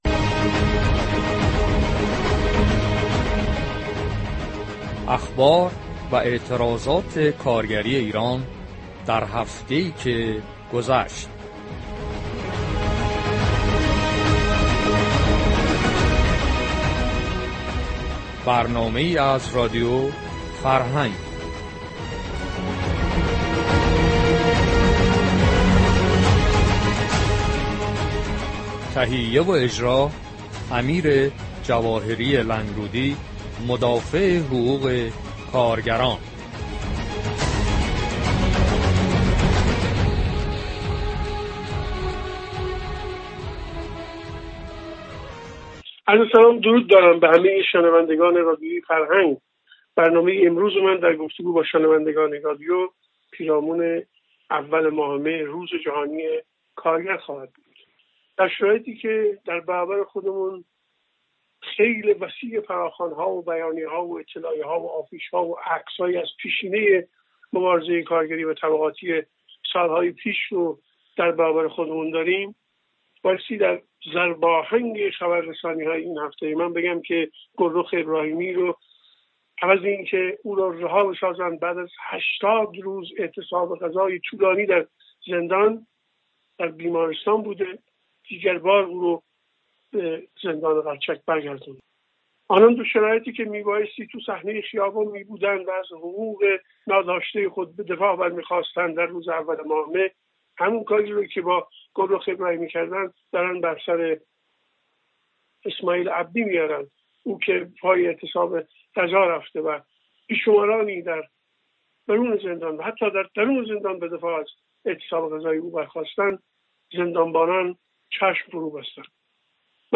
اخبار و اعتراضات کارگری